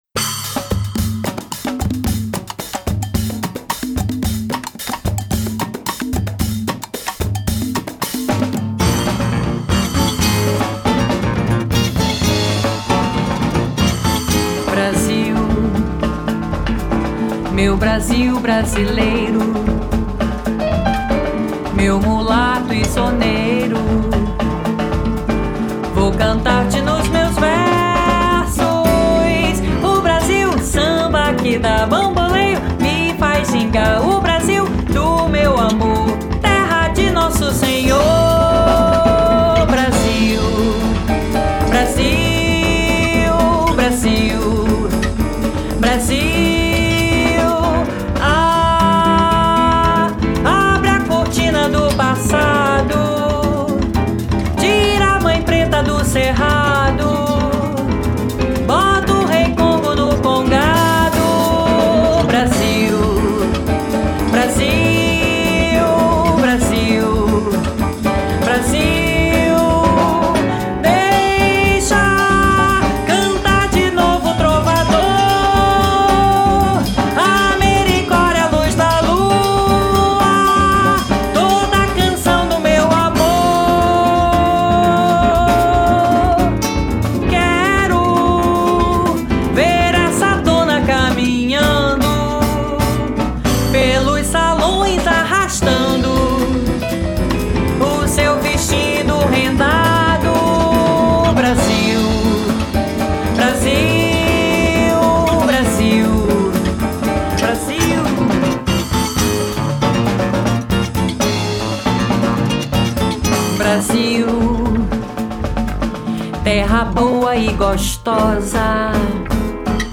Listen LIVE [] É Luxo So (*.mp3 format)
musical ensemble of six talented musicians